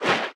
Sfx_creature_babypenguin_swim_fast_04.ogg